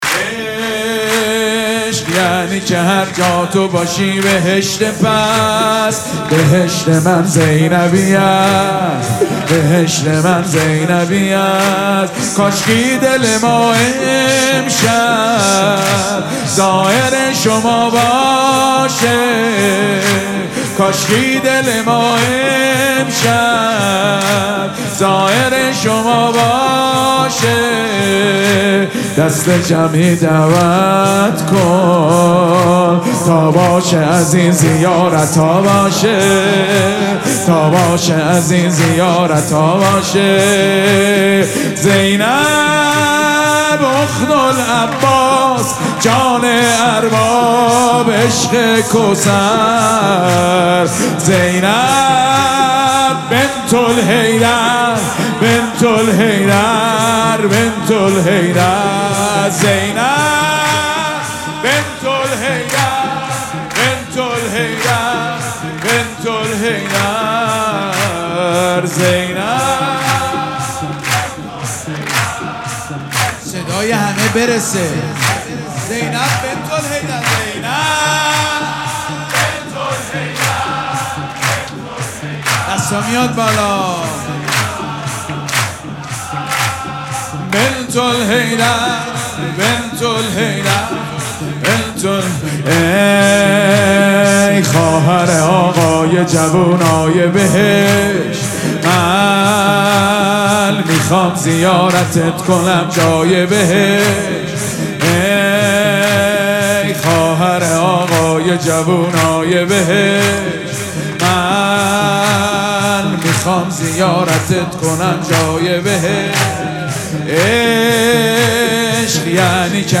مراسم جشن ولادت حضرت زینب (سلام الله علیها)
‌‌‌‌‌‌‌‌‌‌‌‌‌حسینیه ریحانه الحسین سلام الله علیها
سرود